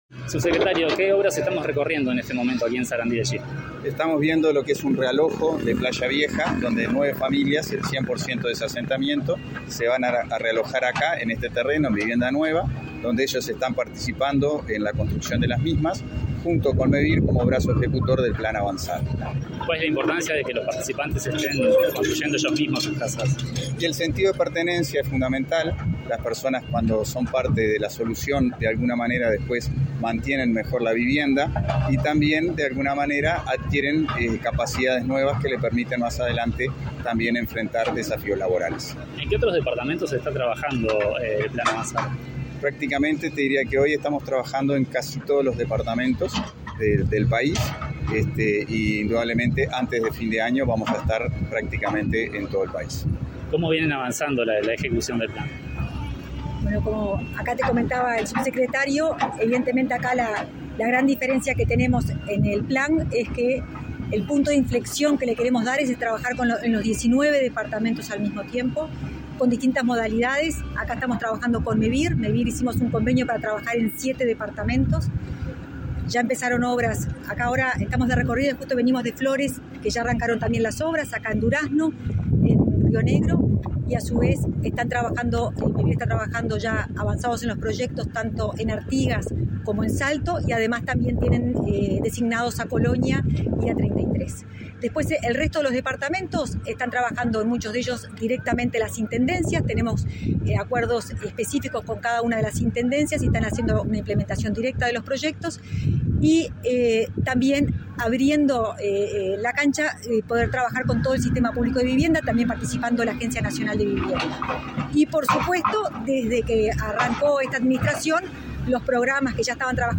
Entrevista a las autoridades del Ministerio de Vivienda y Ordenamiento Territorial
Entrevista a las autoridades del Ministerio de Vivienda y Ordenamiento Territorial 23/02/2023 Compartir Facebook X Copiar enlace WhatsApp LinkedIn Tras una recorrida por las obras de realojo de un asentamiento, ejecutadas por Mevir, Comunicación Presidencial dialogó, este 23 de febrero, con el subsecretario de Vivienda y Ordenamiento Territorial, Tabaré Hackenbruch, y la directora nacional de Integración Social y Urbana, Florencia Arbeleche.